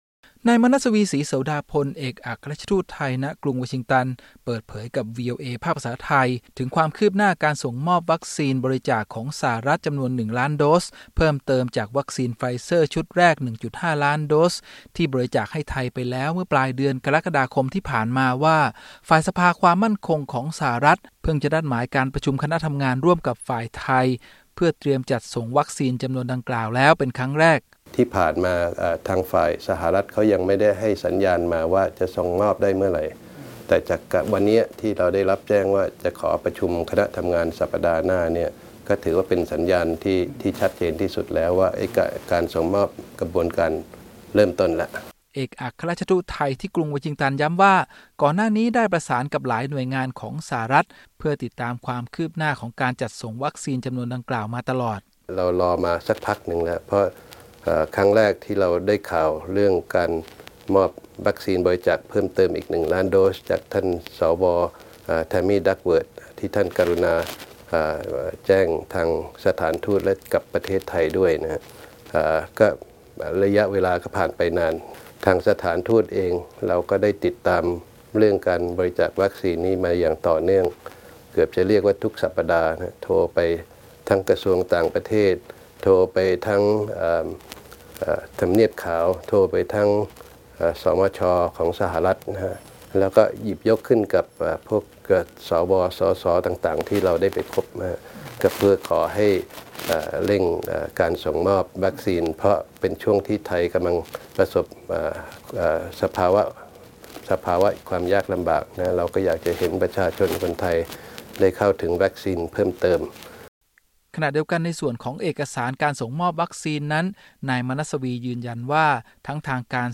Thailand Ambassador to the United States of America, Manasvi Srisodapol talks to VOA Thai during an interview at the Royal Thai Embassy in Washington, D.C. on July 30, 2021.